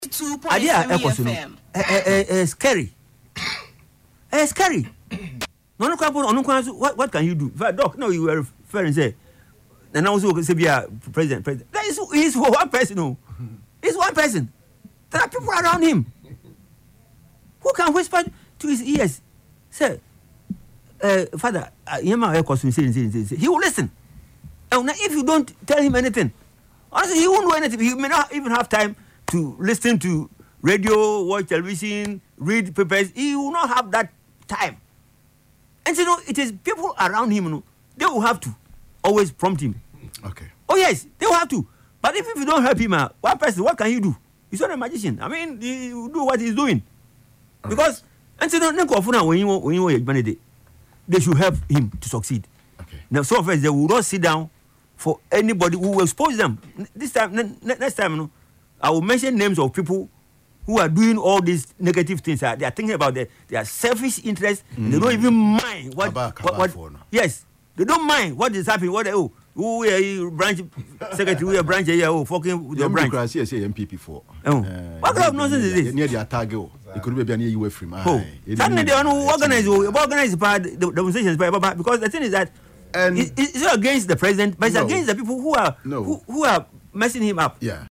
Speaking on Ahotor FM’s Yepe Ahunu programme on Saturday, April 18, he noted that due to the demanding nature of the presidency, President Mahama may not always have the time to closely follow media reports or public discourse.